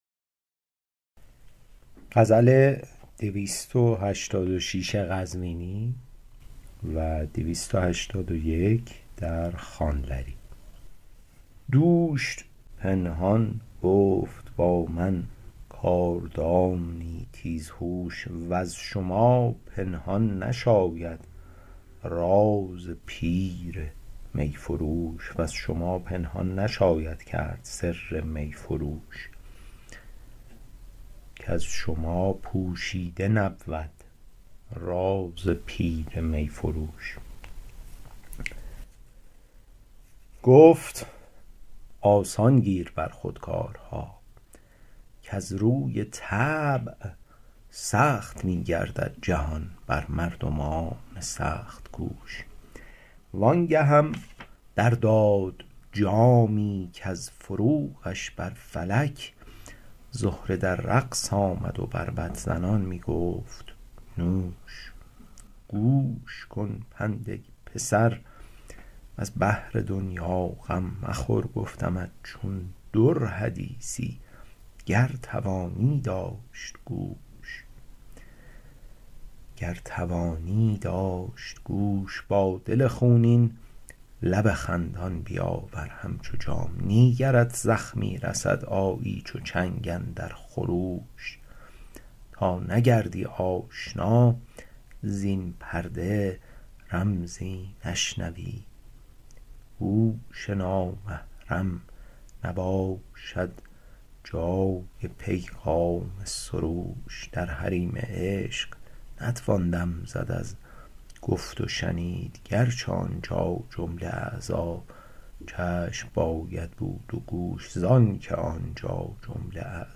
شرح صوتی غزل شمارهٔ ۲۸۶